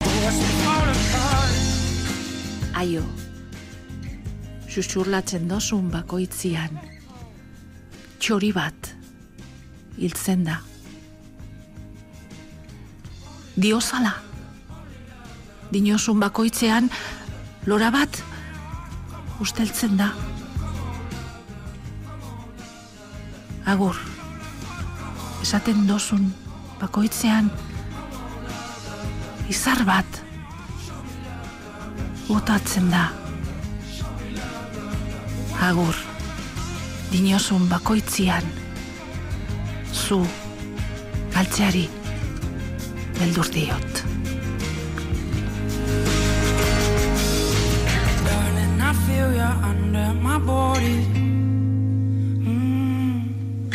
Rapsodeando en euskera un poema de su autoría
Un poema premiado en un concurso literario. Susurros de versos